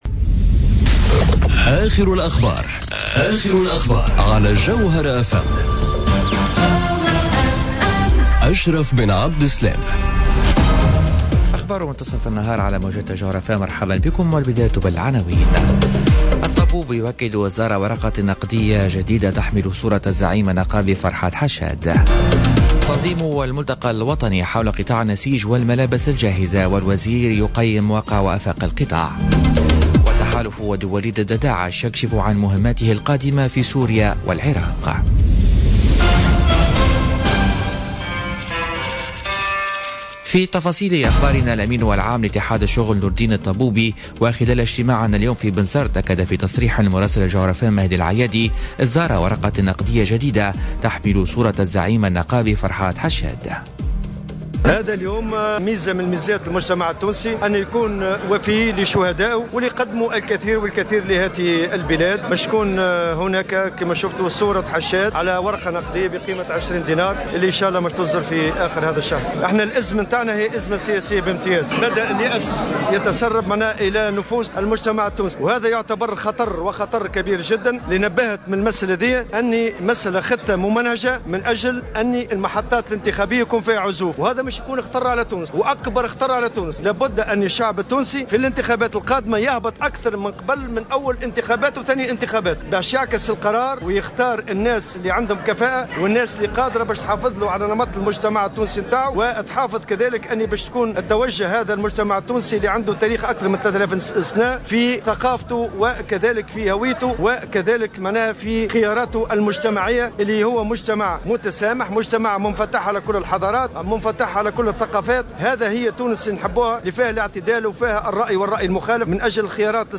نشرة أخبار منتصف النهار ليوم الإربعاء 20 ديسمبر 2017